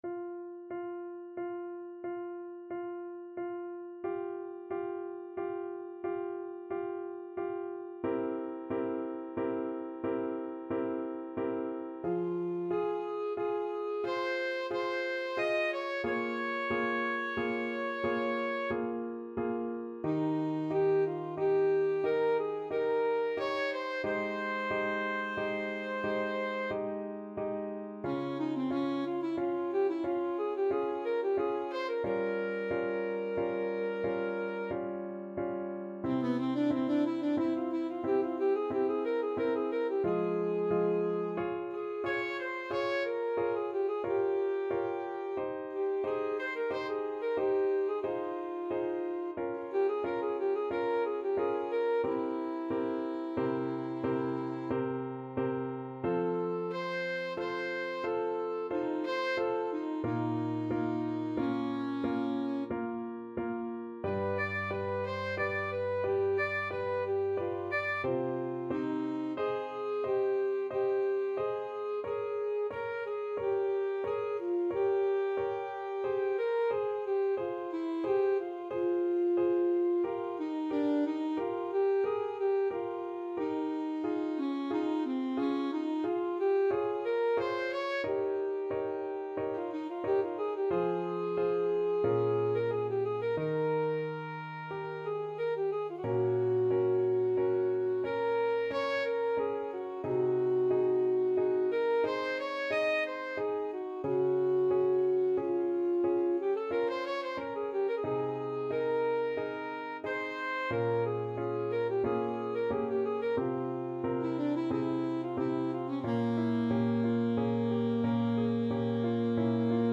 Classical Bach, Johann Sebastian BWV 974 - Adagio (Bach/Marcello) Alto Saxophone version
Alto Saxophone
Adagio =45
3/4 (View more 3/4 Music)
F minor (Sounding Pitch) D minor (Alto Saxophone in Eb) (View more F minor Music for Saxophone )
Classical (View more Classical Saxophone Music)